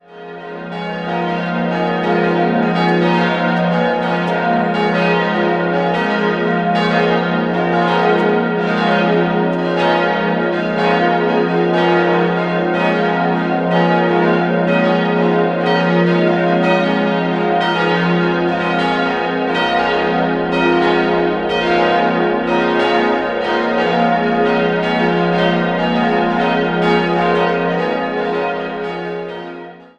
Die Weihe erfolgte im Jahr 1972. 5-stimmiges Geläute: f'-as'-b'-c''-es'' Die Glocken wurden im Jahr 2000 von der Gießerei Bachert in Heilbronn gegossen.